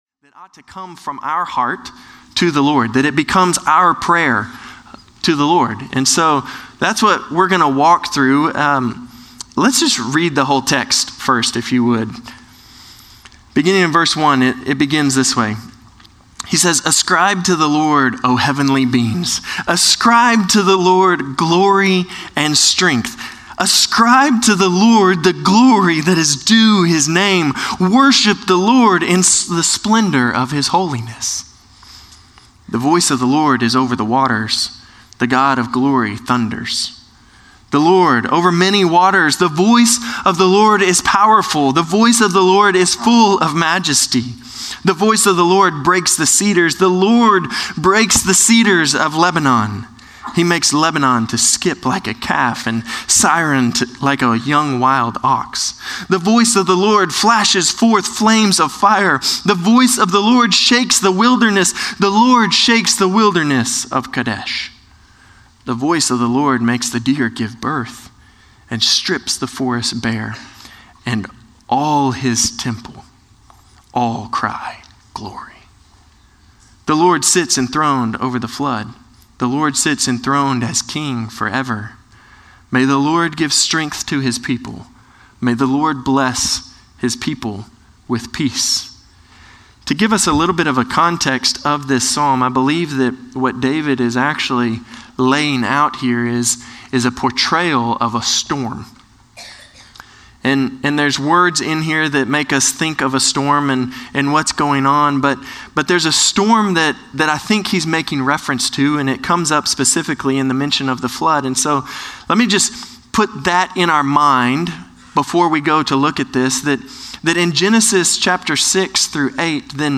Norris Ferry Sermons Oct. 20, 2024 -- The Book of Psalms -- Psalm 29 Oct 20 2024 | 00:26:27 1x 00:00 / 00:26:27 Subscribe Share Spotify RSS Feed Share Link Embed